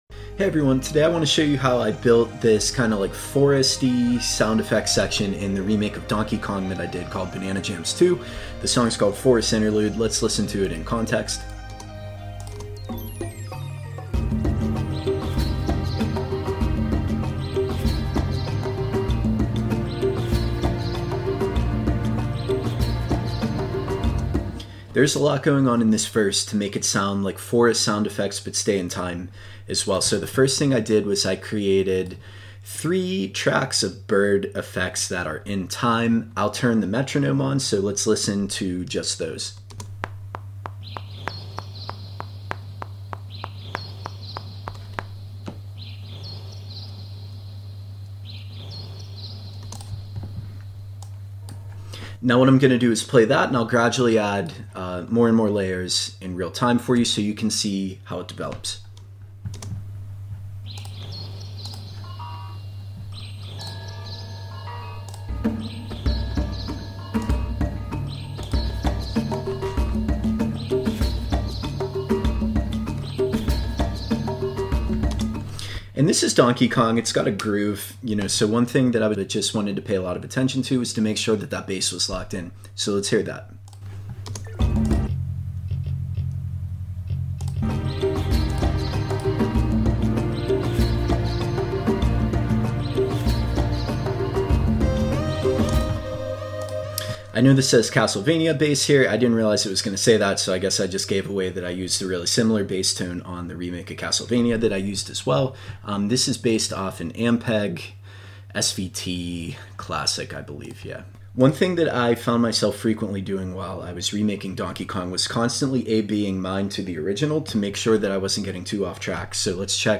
time-aligning jungle sound effects and tropical percussion